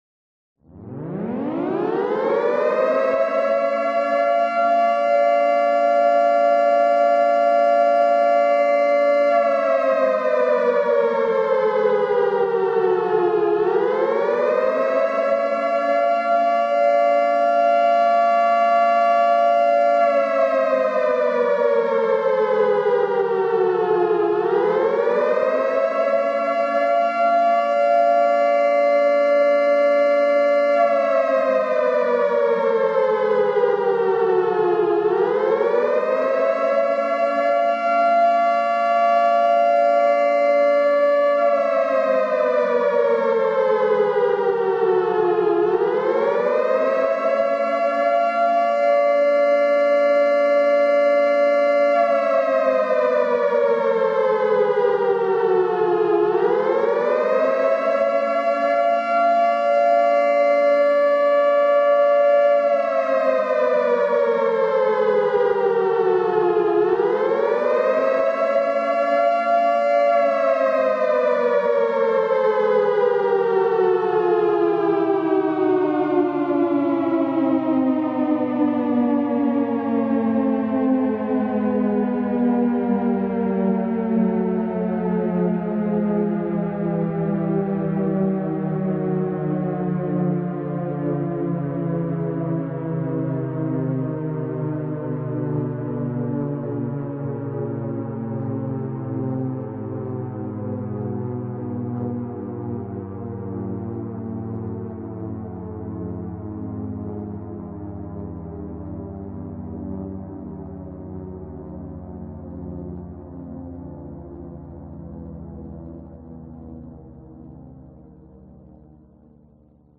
Sirène-nucléaire-15-minute---nuclear-alarm-siren.mp3
JAirCrpr7hi_Sirène-nucléaire-15-minute---nuclear-alarm-siren.mp3